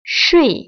[shuì] 수이  ▶